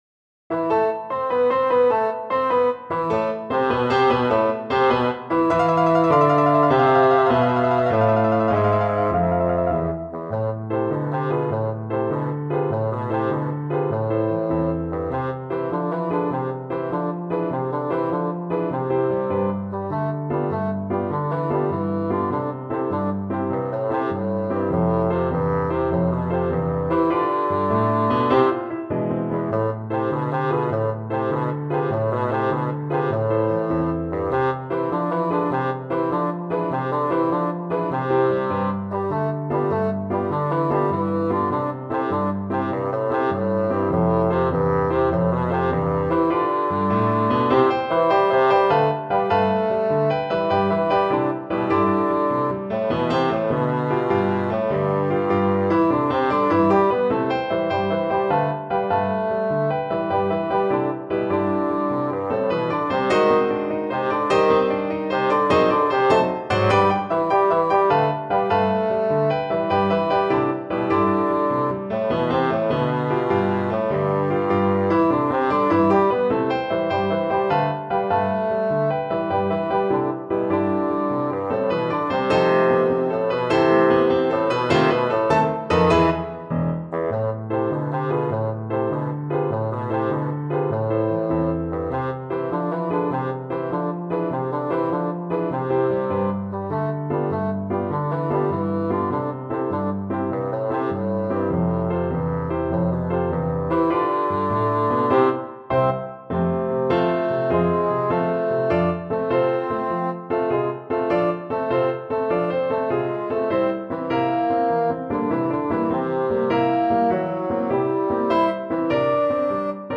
Bassoon Music
is a popular children's song.